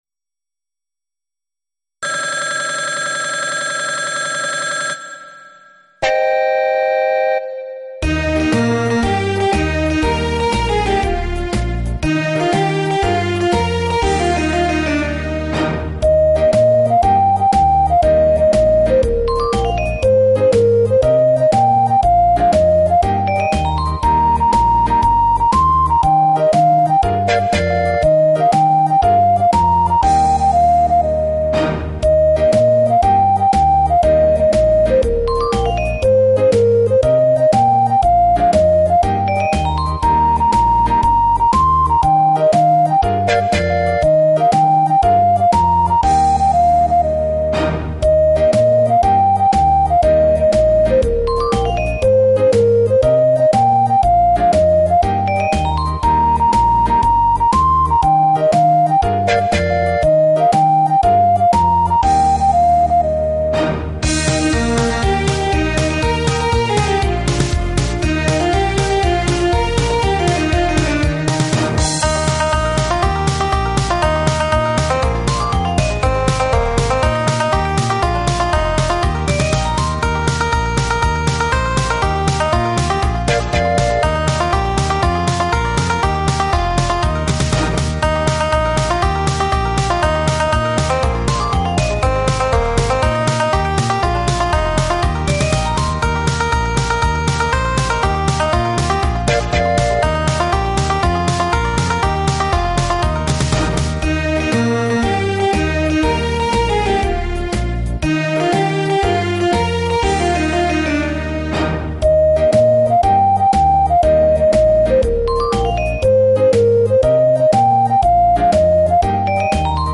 MTDS_instrumental.mp3.mp3